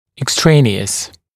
[ɪk’streɪnɪəs] [ek-][ик’стрэйниоэс] [эк-]посторонний, внешний, инородный